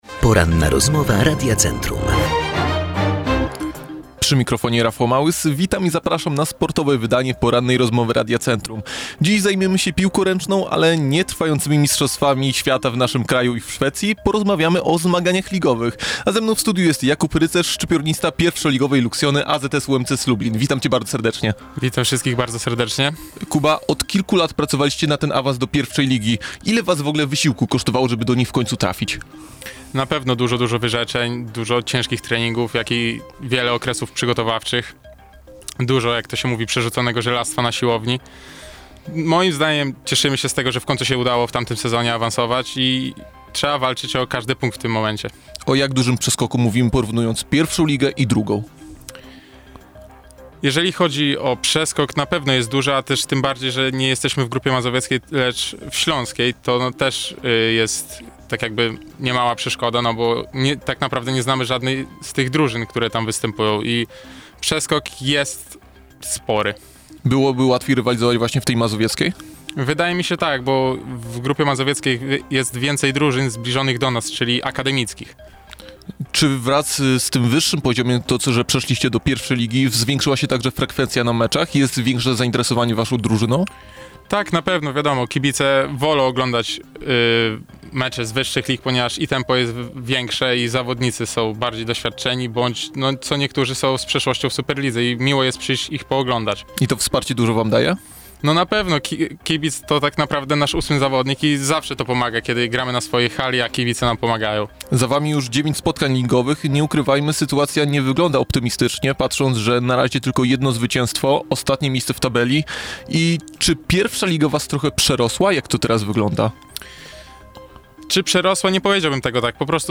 Cała rozmowa dostępna poniżej.